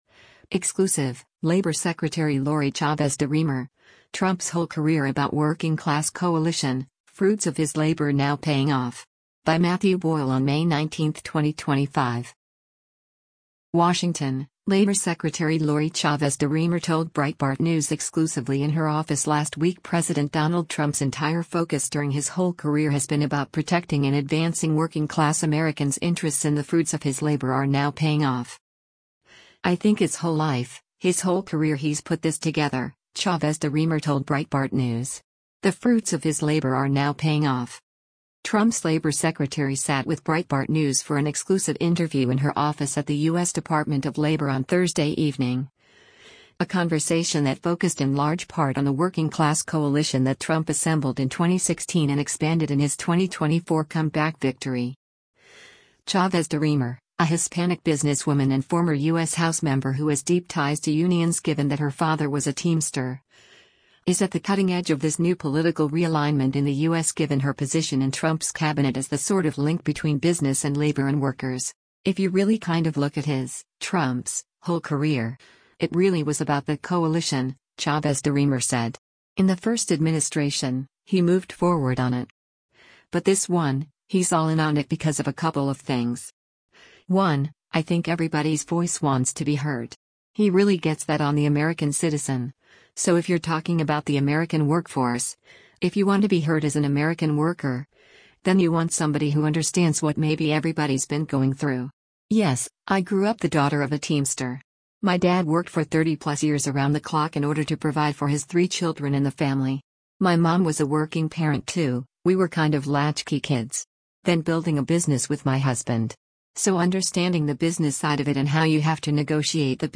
Trump’s Labor Secretary sat with Breitbart News for an exclusive interview in her office at the U.S. Department of Labor on Thursday evening, a conversation that focused in large part on the working class coalition that Trump assembled in 2016 and expanded in his 2024 comeback victory.